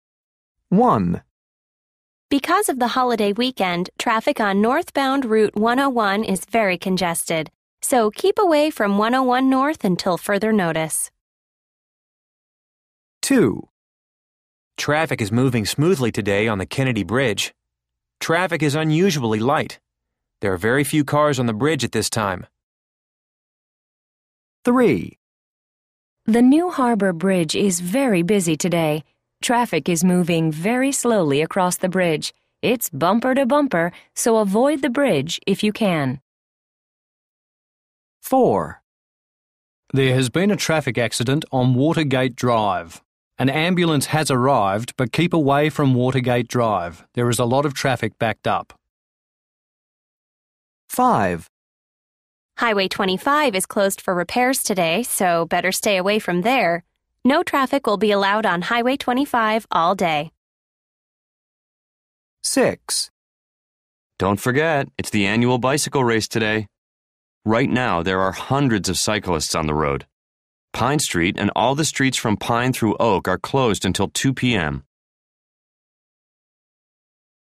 People are making announcements about traffic conditions.